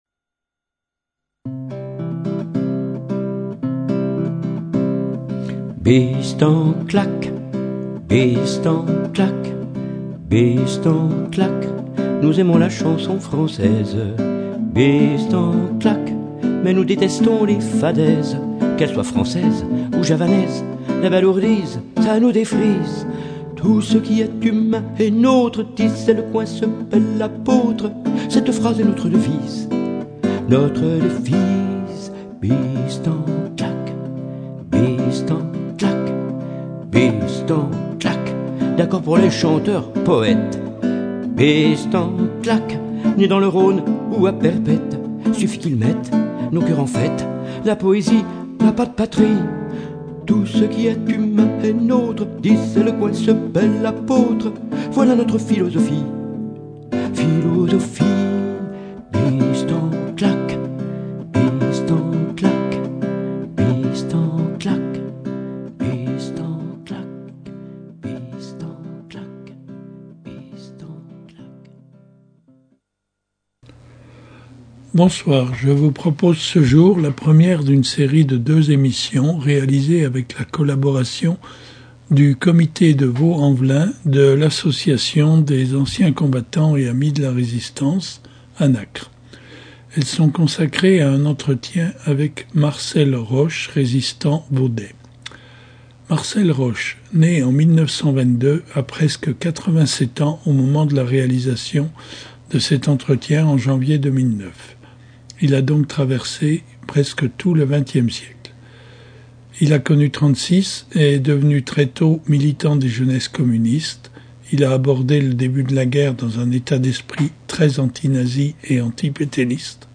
deux émissions réalisées avec la collaboration du comité de VAULX EN VELIN de l’association nationale des anciens combattants et amis de la résistance ( ANACR ) à partir d’un entretien